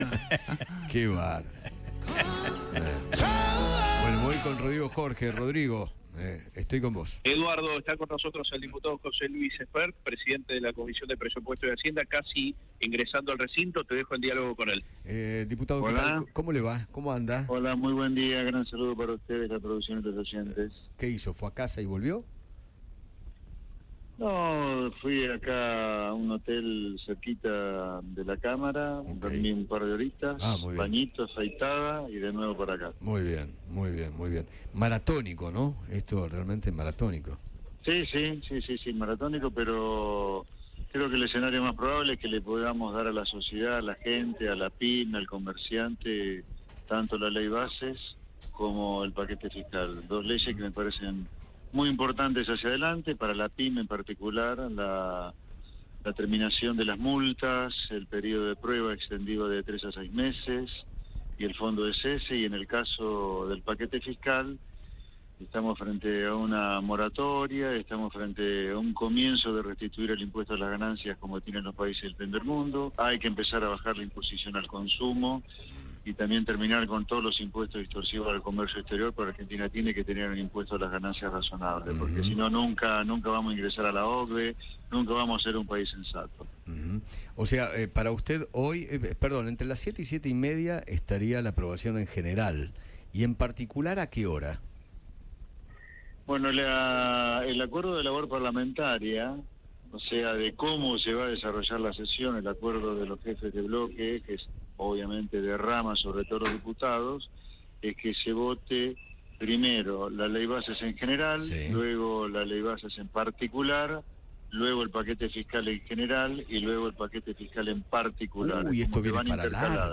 José Luis Espert, diputado nacional, conversó con Eduardo Feinmann, en medio del debate de la Ley Bases en la Cámara de Diputados.